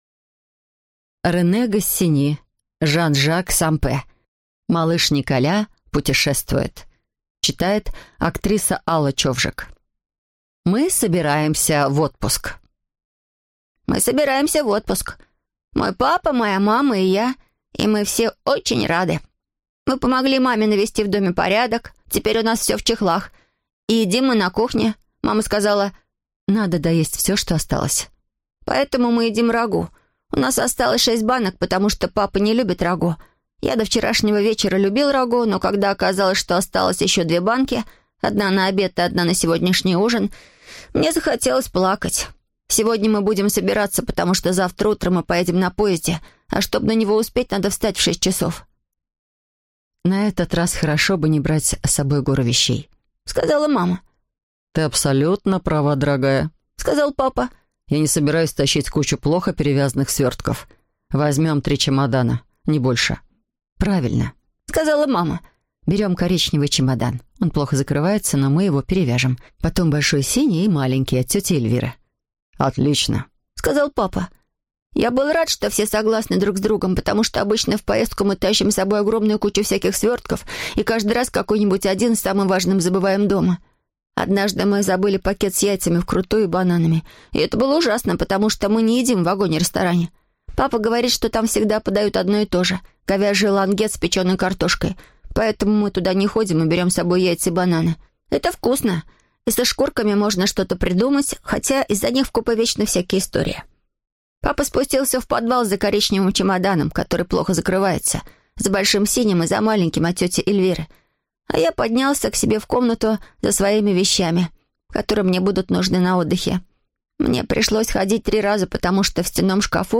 Аудиокнига Малыш Николя путешествует | Библиотека аудиокниг